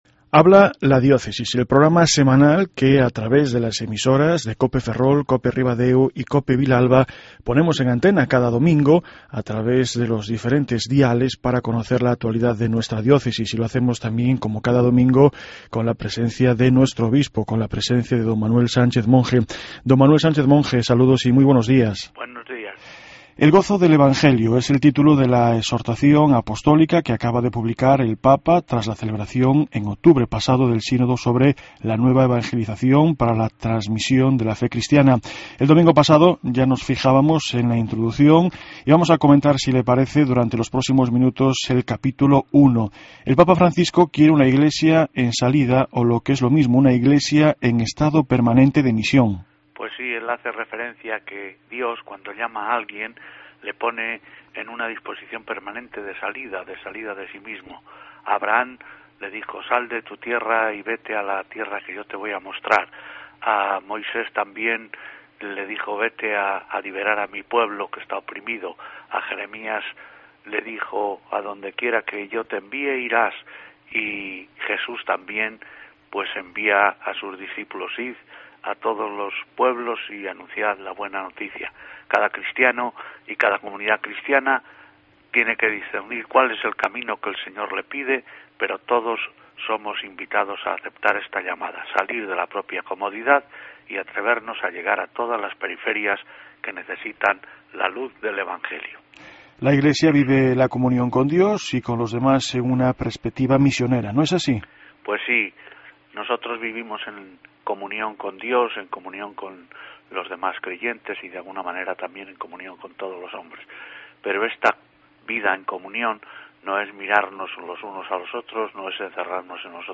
El Obispo de nuestra diócesis, Monseñor Sánchez Monje nos habla en esta jornada sobre el Gozo del Evangelio y de una Iglesia en permanente misión